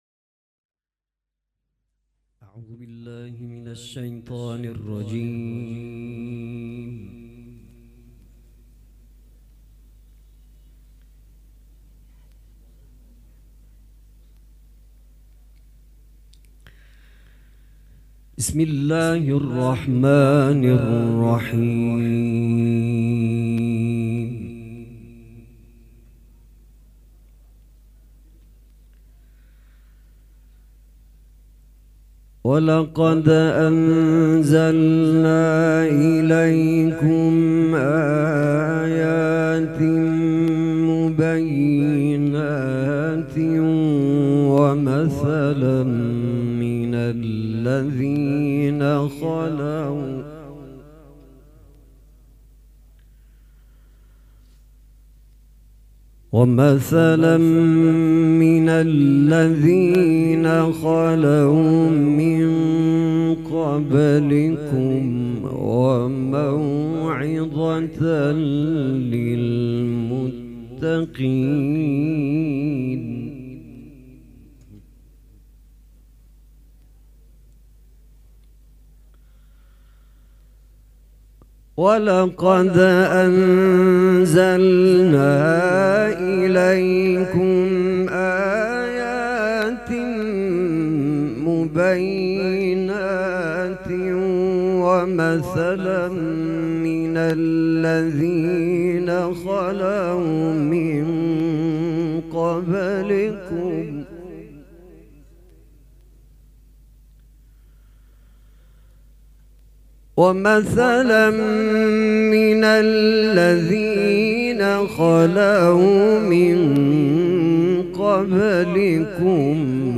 قرائت قرآن کریم
سبک اثــر قرائت قرآن